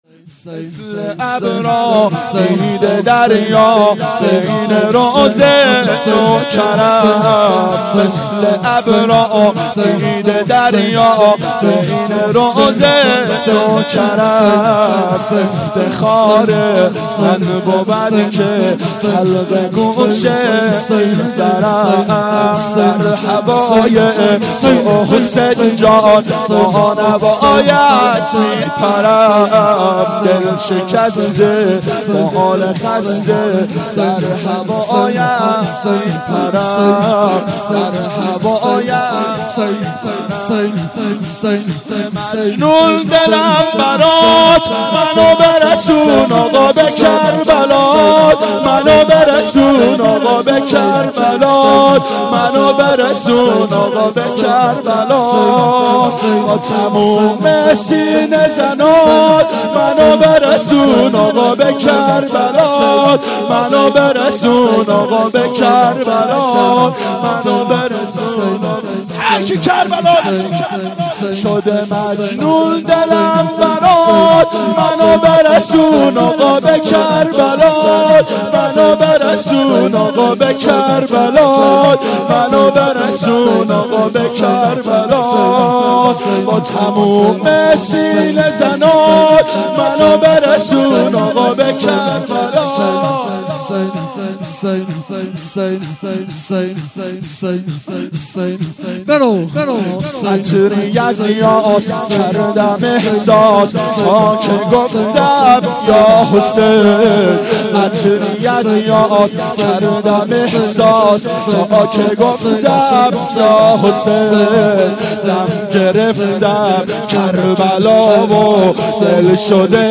• فاطمیه اول 92 هیأت عاشقان اباالفضل علیه السلام منارجنبان